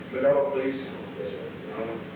Secret White House Tapes
Conversation No. 442-46
Location: Executive Office Building
The President met with an unknown man.